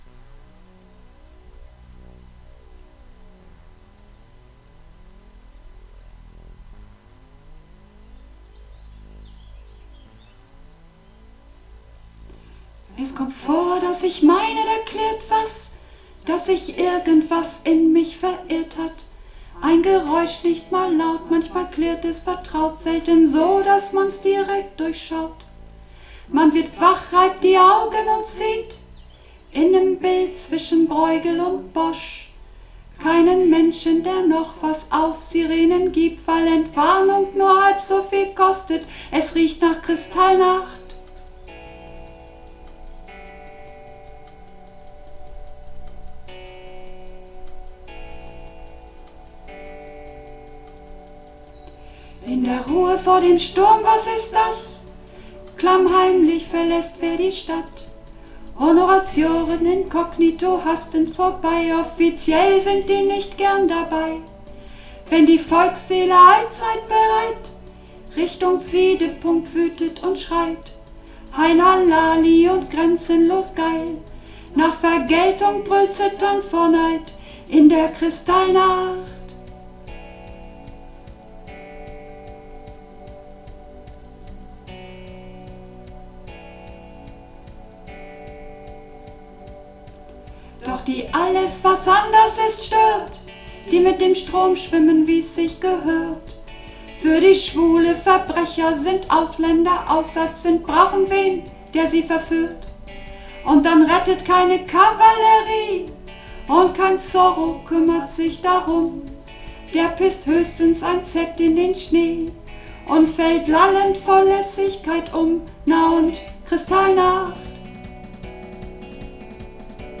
Runterladen (Mit rechter Maustaste anklicken, Menübefehl auswählen)   Kristallnacht (Sopran - Nur Stimme)
Kristallnacht__3a_Sopran_Nur_Stimme.mp3